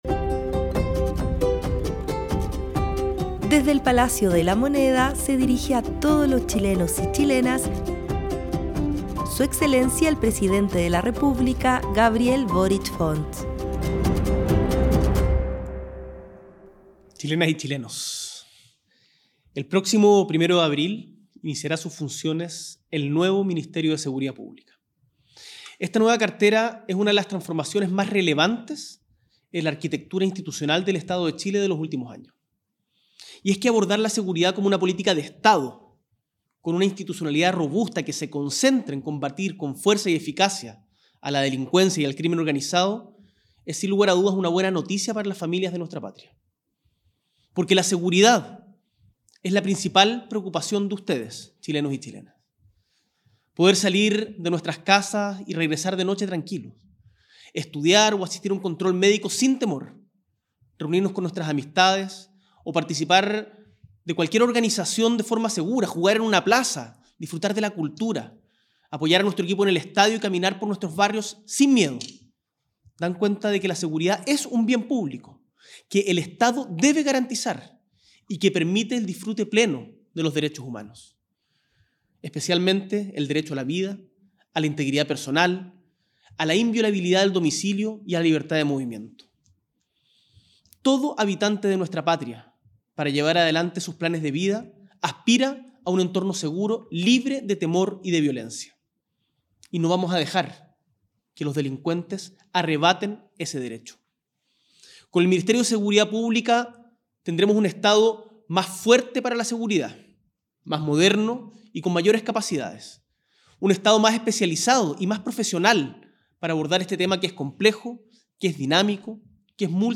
S.E. el Presidente de la República, Gabriel Boric Font,  realiza Cadena Nacional por nuevo Ministerio de Seguridad.